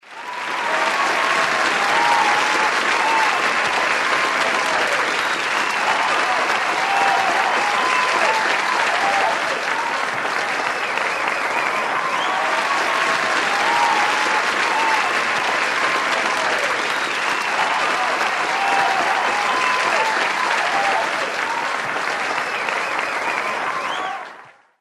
+Applause
Category: Radio   Right: Both Personal and Commercial